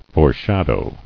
[fore·shad·ow]